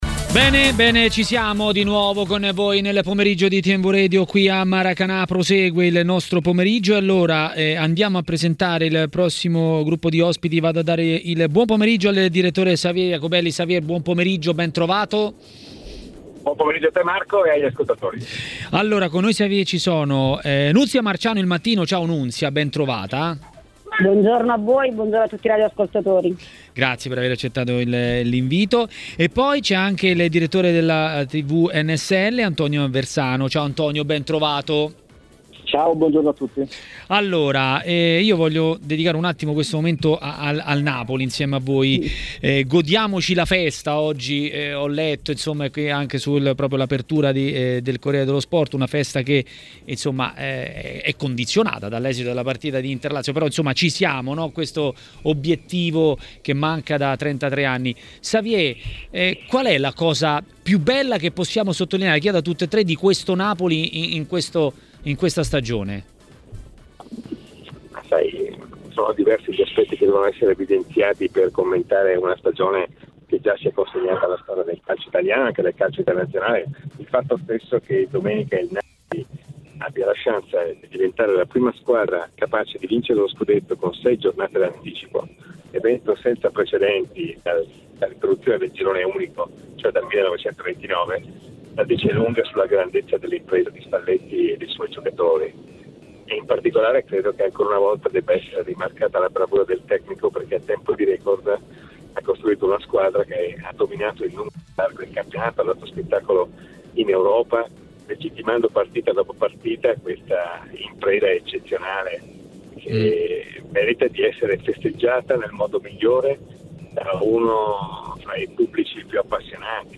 A commentare le notizie riguardanti il Napoli a Maracanà, trasmissione di TMW Radio, è stato il direttore Xavier Jacobelli.